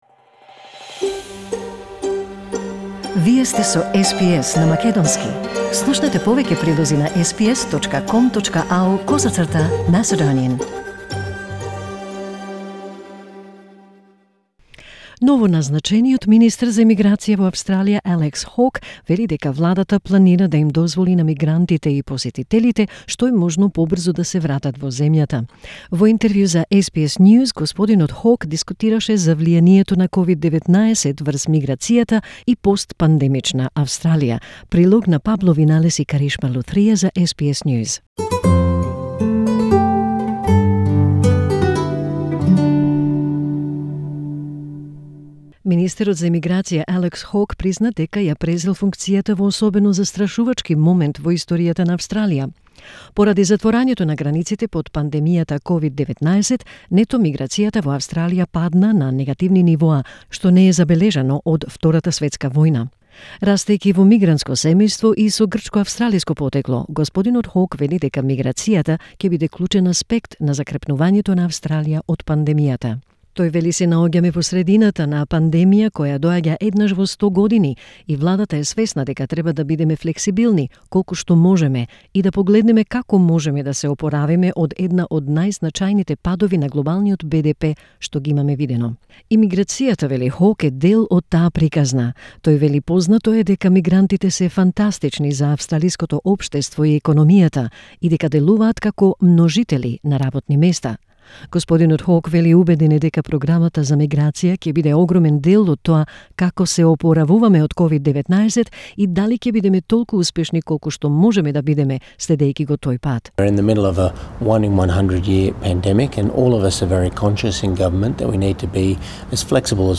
Australia's newly-appointed Immigration Minister Alex Hawke says the government is planning to allow migrants and visitors back into the country as soon as possible. In an interview with SBS News, Mr Hawke discussed the impacts of COVID-19 on migration and a post-pandemic Australia.